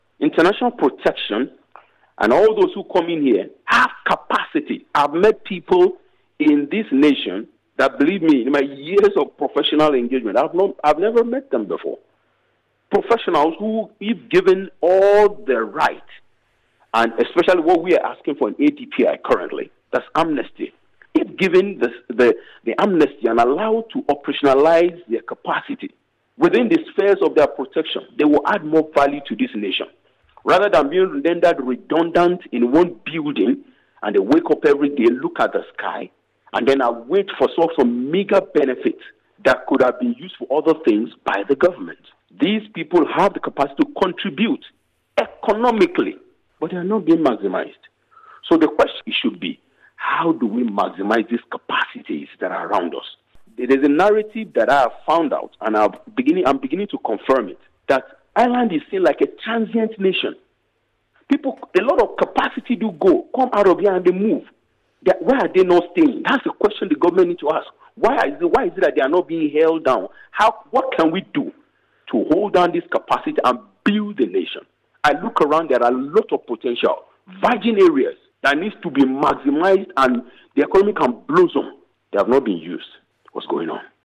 He says there is huge potential among the people in the system: